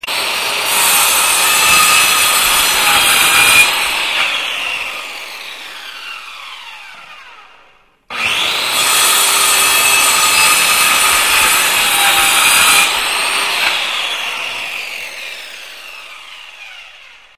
На этой странице собраны различные звуки болгарки: от коротких резких скрежетов до продолжительной работы инструмента.
Осторожно: громко!
Звук болгарки в руке